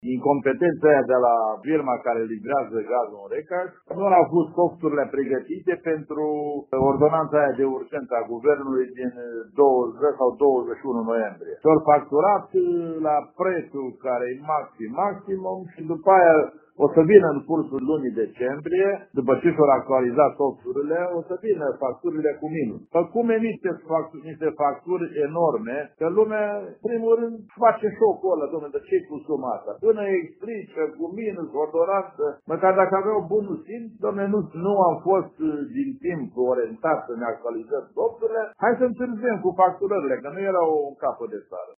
Primarul din Recaș, Pavel Teodor, susține că furnizorul de gaz a emis facturile fără plafonările prevăzute de lege și că situația va fi corectată.